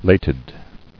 [lat·ed]